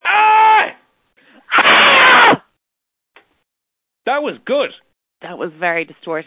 Screams from November 29, 2020
• When you call, we record you making sounds. Hopefully screaming.